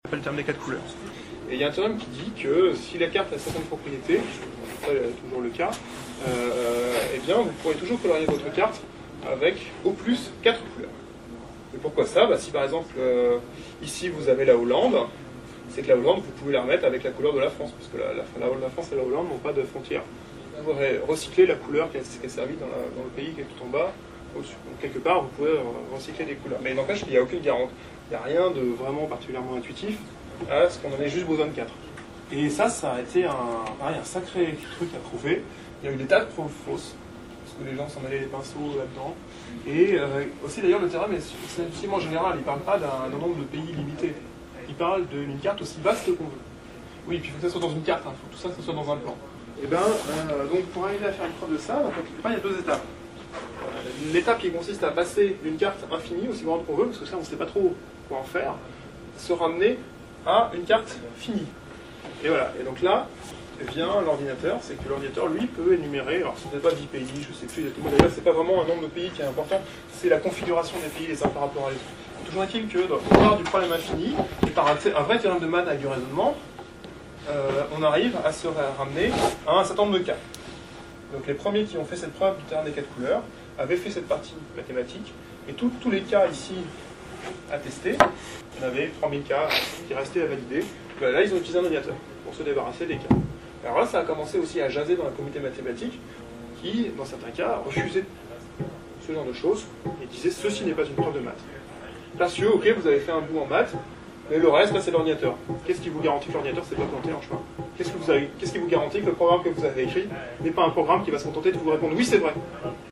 Interview théorème des quatre couleurs